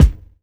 Kick
Original creative-commons licensed sounds for DJ's and music producers, recorded with high quality studio microphones.
Rich Lows Steel Kick Drum Sample F# Key 08.wav
perfect-steel-kick-drum-sound-f-sharp-key-10-Q6M.wav